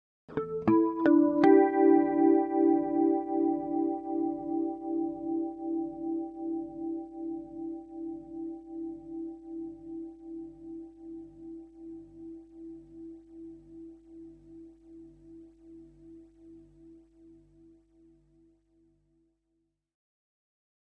Electric Guitar Harmonic Arpeggio With Flange 4